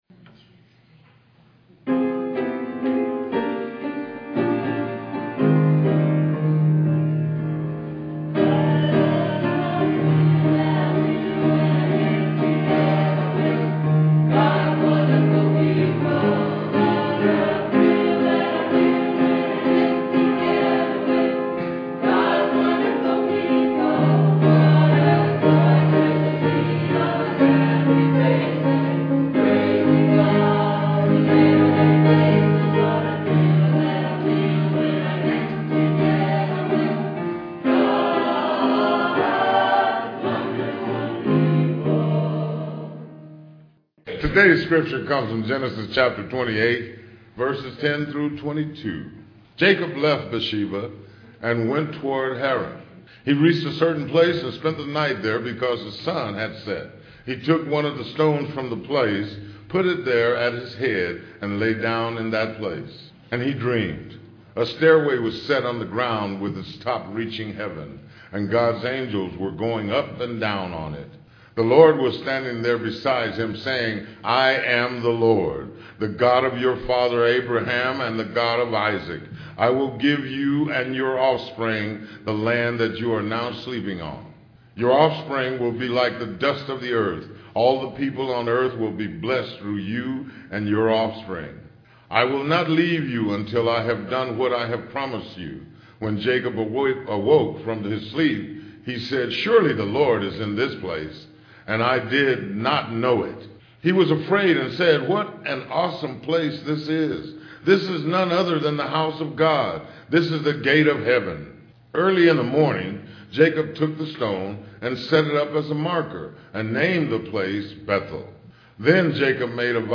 Piano offertory